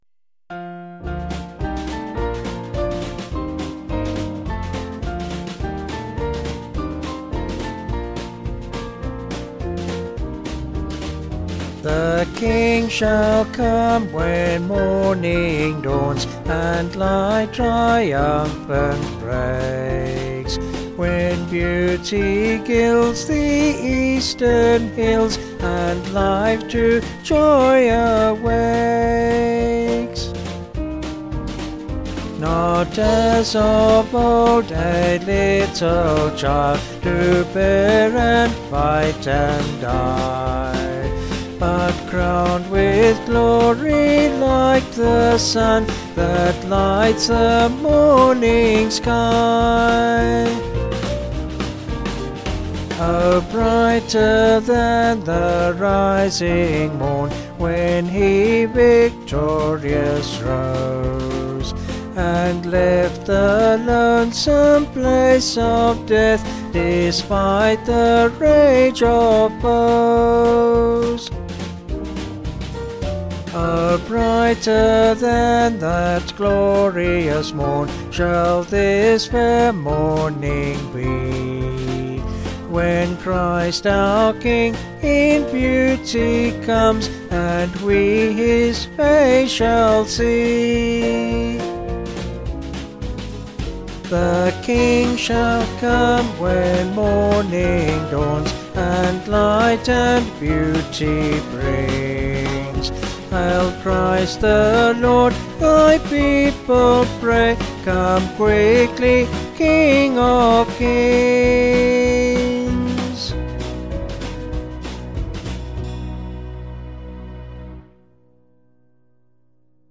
Vocals and Band   263.3kb Sung Lyrics